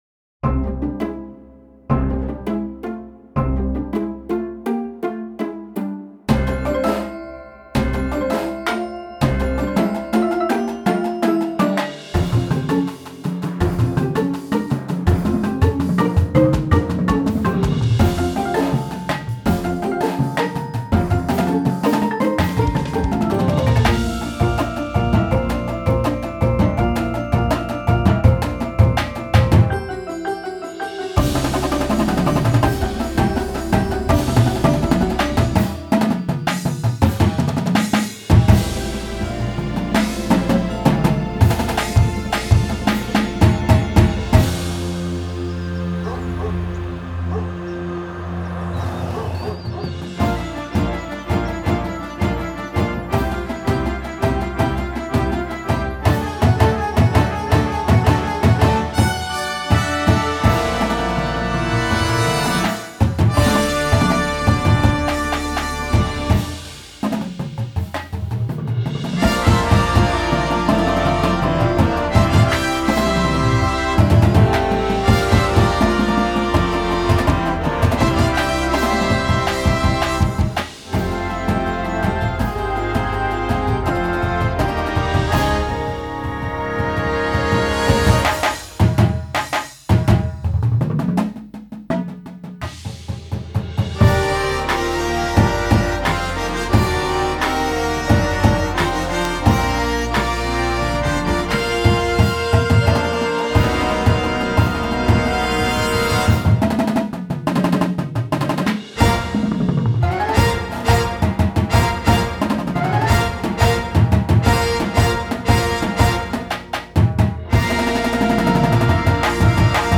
Marching Band Shows
Winds
Percussion